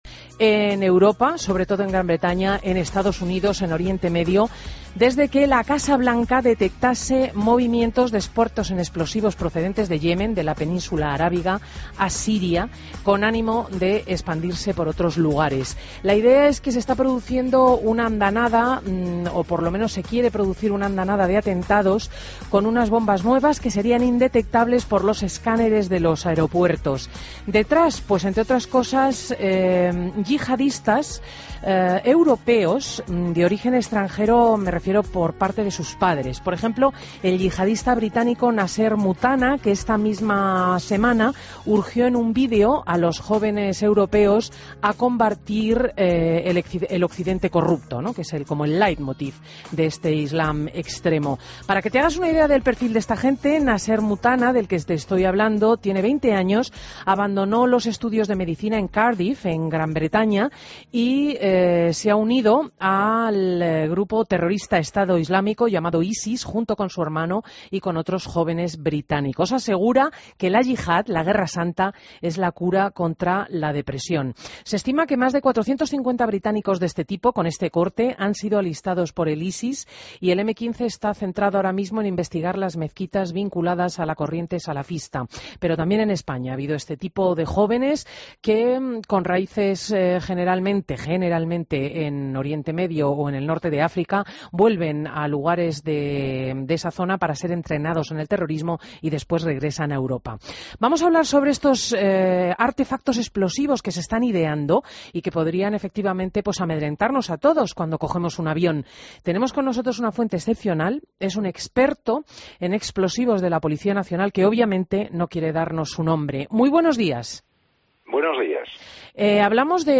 Policía experto en explosivos, entrevistado en Fin de Semana COPE